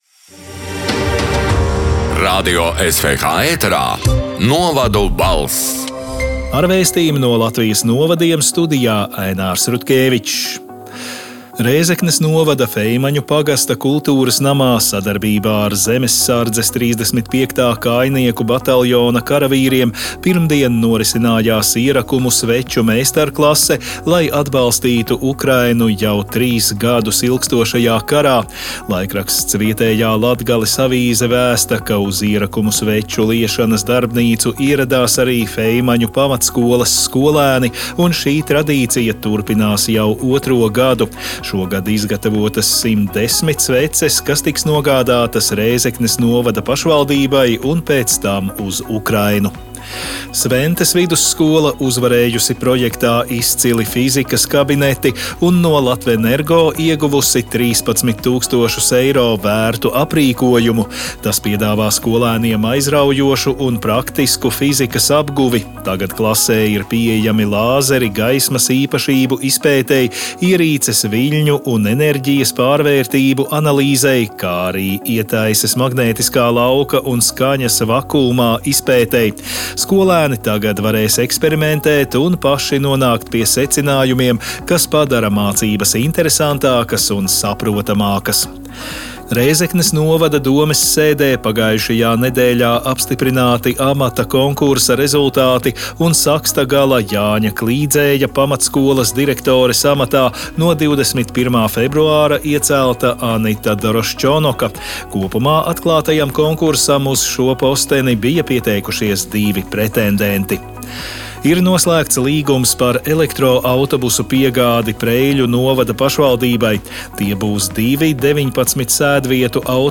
“Novadu balss” 28. februāra ziņu raidījuma ieraksts: